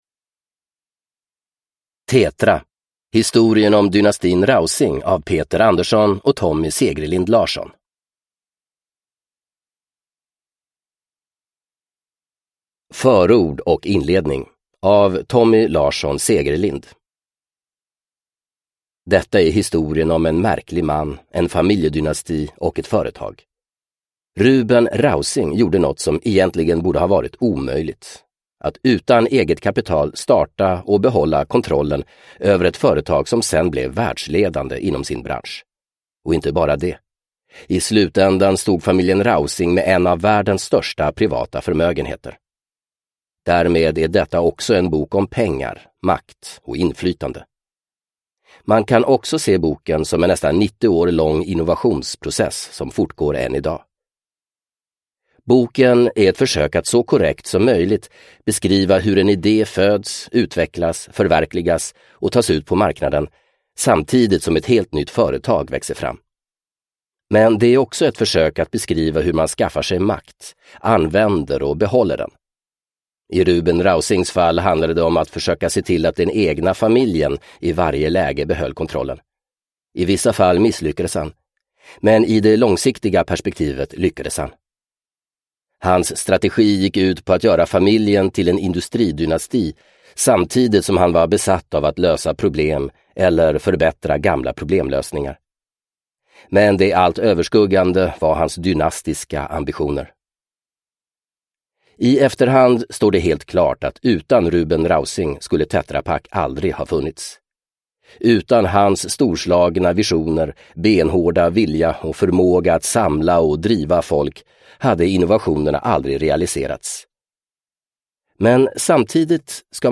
Tetra : historien om dynastin Rausing – Ljudbok – Laddas ner